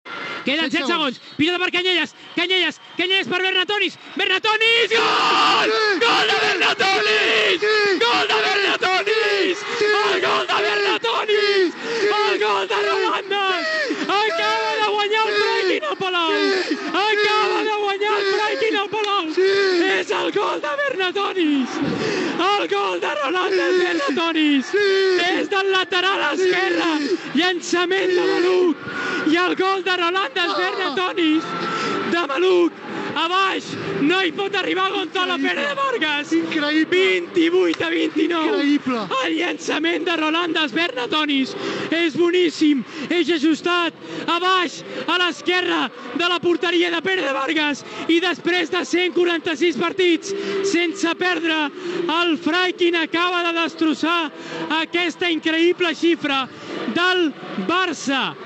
Últims segons de la transmissió del partit d'handbol masculí la la Lliga entre el FC Barcelona i el Larsa- Fraikin BM Granollers.
Esportiu